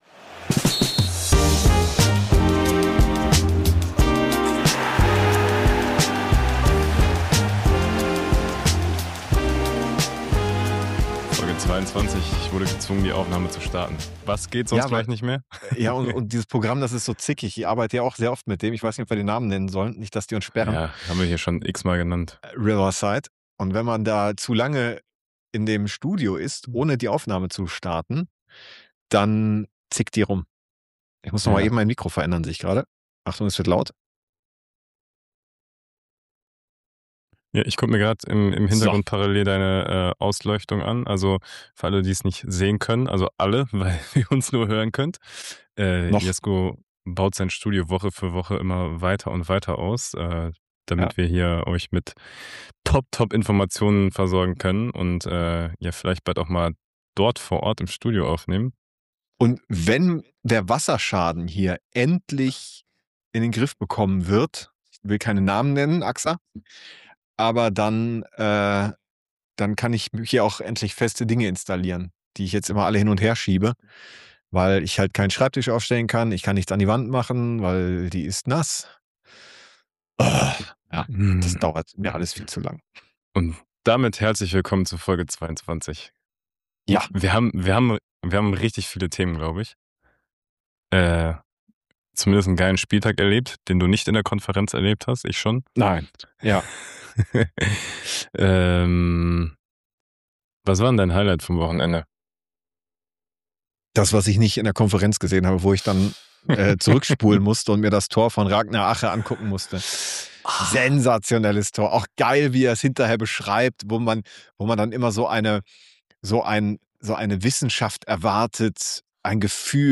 Folge 22 startet mit Technik Struggle und Studiobaustelle.
Riverside zickt, das Mikro wird noch schnell justiert und irgendwo im Hintergrund kämpft eine Wand immer noch mit Wasserschaden.